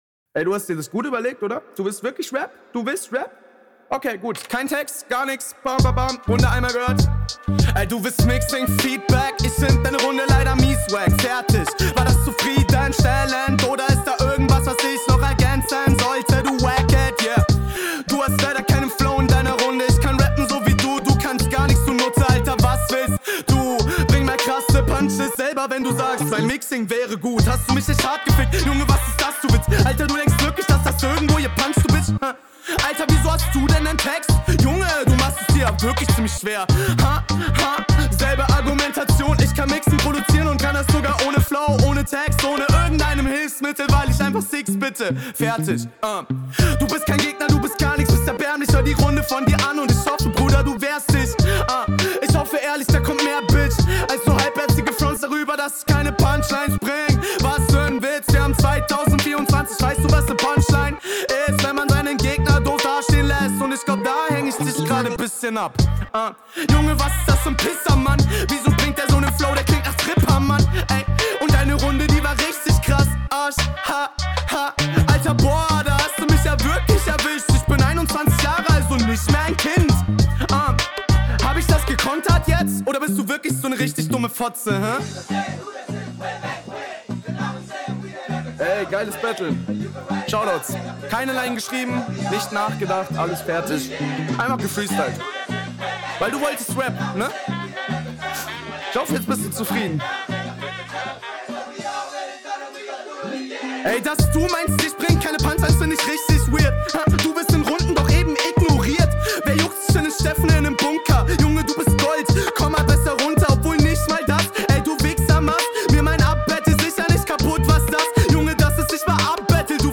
wärn da nicht drei stellen gewesen, bei denen man den freestyle im flow merkt, wär …
Klingt nice! mag den Stimmeinsatz. deutlich, deutlich besser als die HR1.